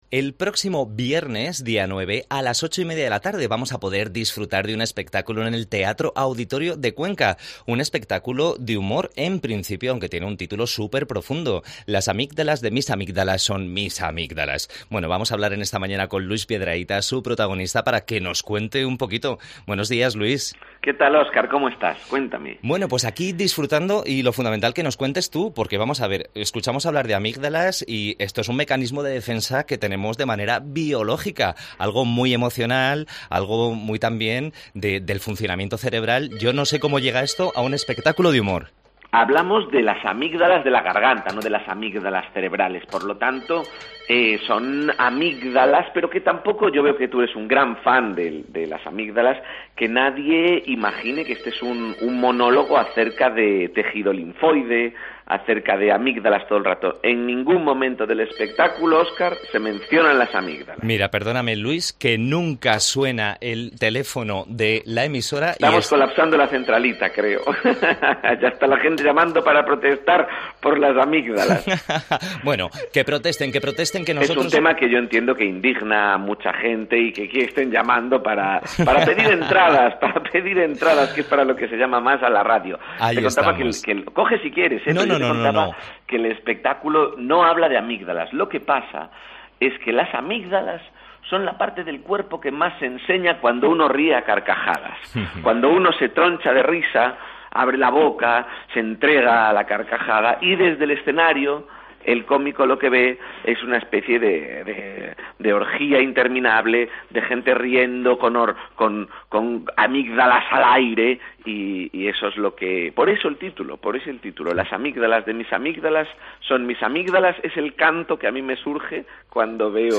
Entrevista al humorista Luis Piedrahita que actúa el 9 de junio a las 20:30 en el teatro auditorio de Cuenca con su espectaculo "Las amigdalas de mis amigdalas son mis amigdalas"